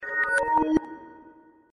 notify_pause.wav